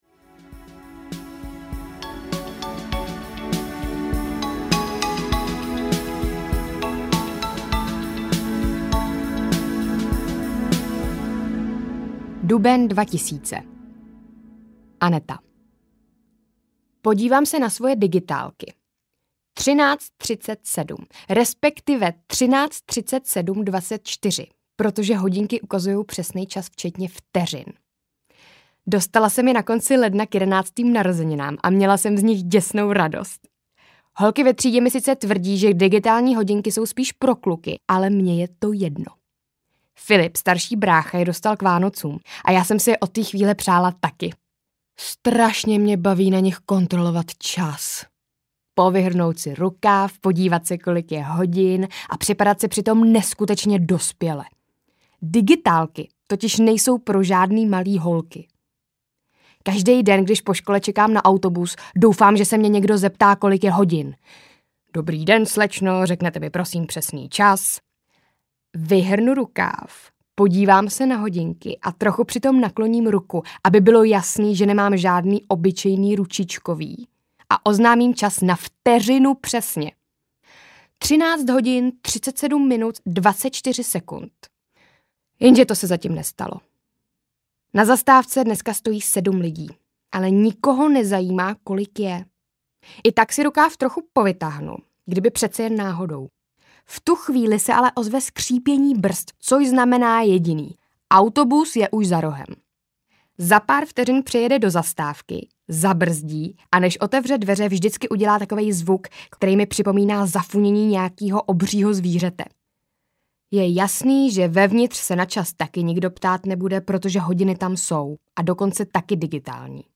Hezký, ale narovnej se! audiokniha
Ukázka z knihy